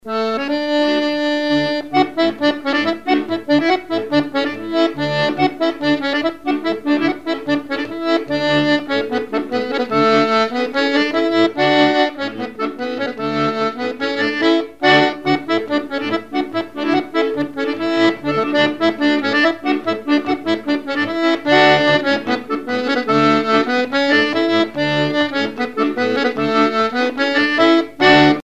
Divertissements d'adultes - Couplets à danser
danse : branle : courante, maraîchine
instrumentaux à l'accordéon diatonique
Pièce musicale inédite